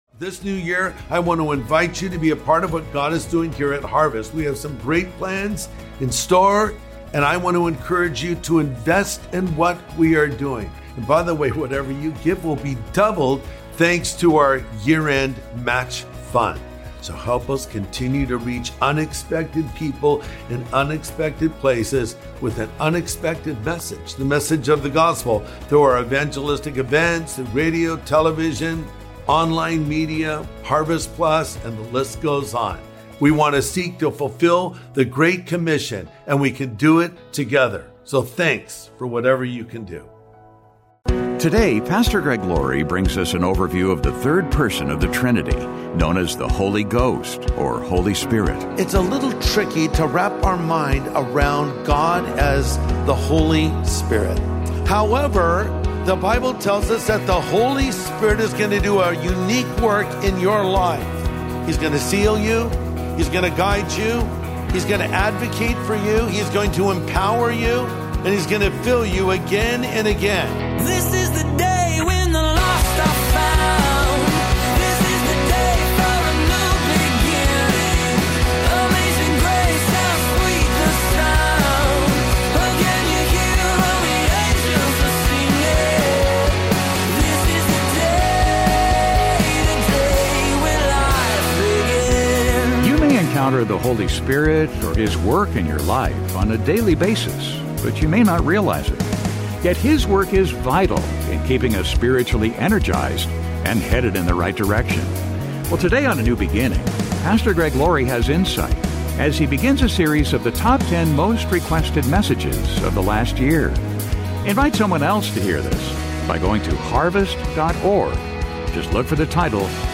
You may encounter the Holy Spirit or His work in your life on a daily basis, but you may not realize it. Yet His work is vital in keeping us spiritually energized, and headed in the right direction. Today on A NEW BEGINNING, Pastor Greg Laurie has insight as he begins a series of the top 10 most requested messages of this last year.